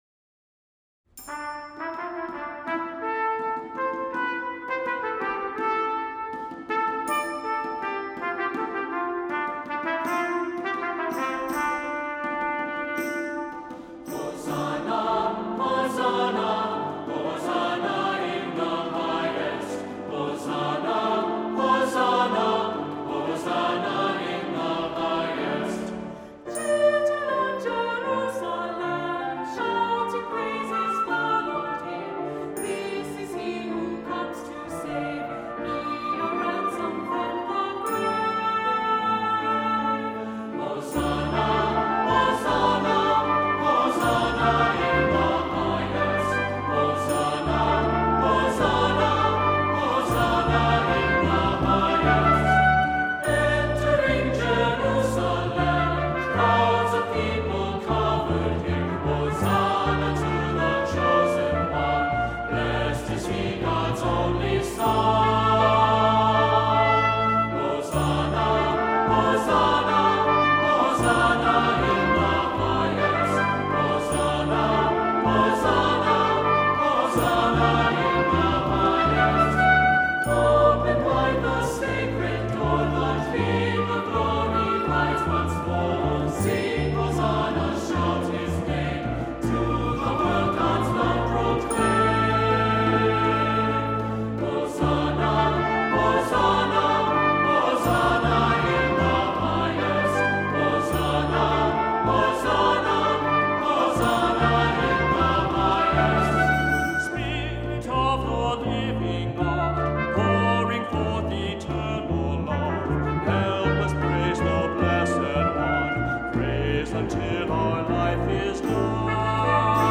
Voicing: SAT; Cantor; Assembly